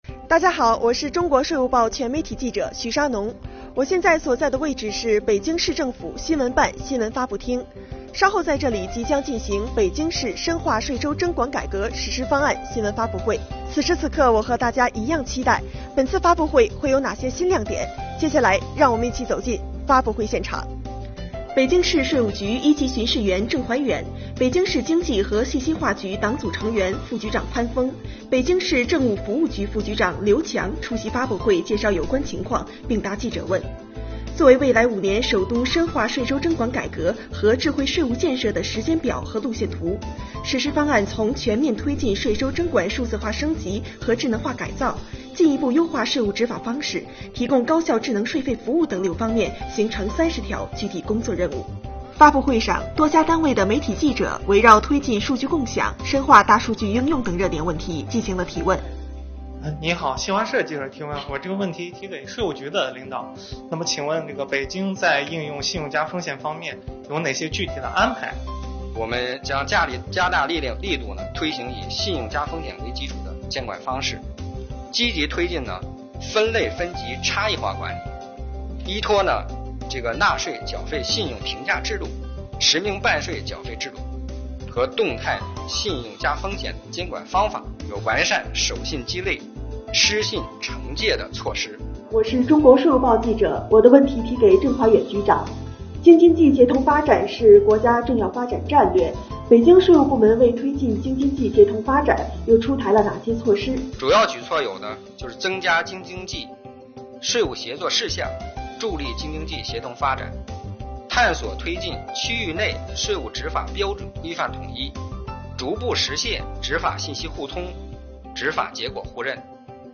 标题: 直击发布会现场 看北京税收征管改革新亮点
新闻发布会现场。